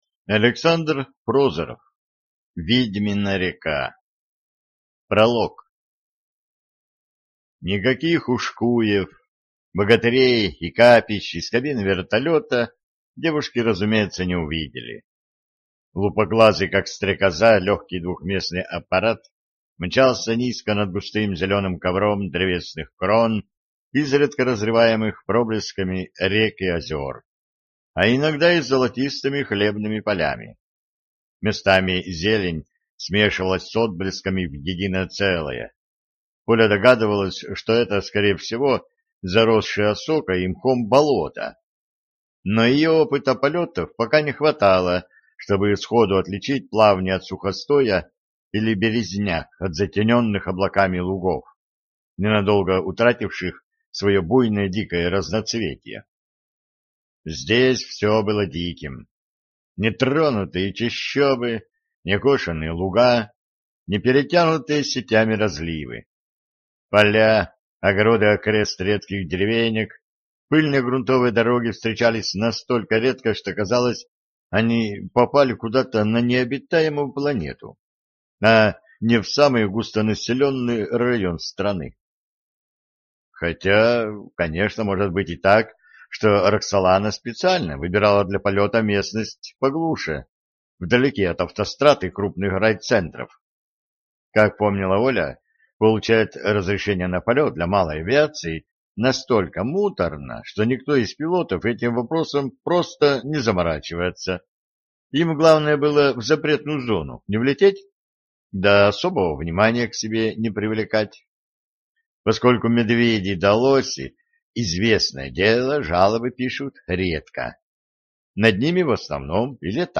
Аудиокнига Ведьмина река | Библиотека аудиокниг